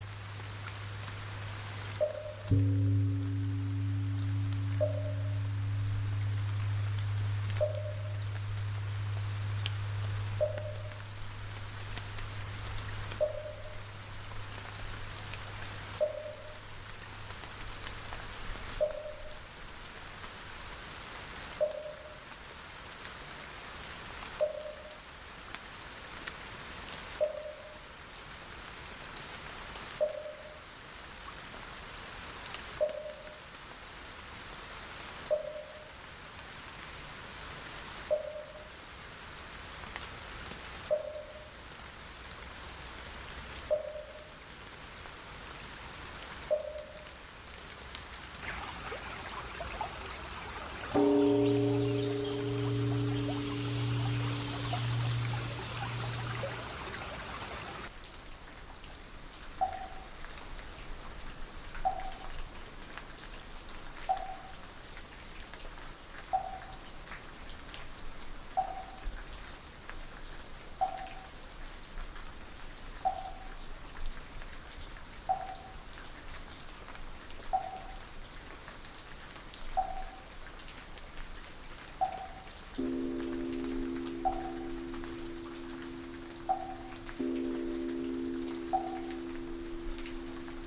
雨中的寺庙鈡声
佛寺雨鈡声.mp3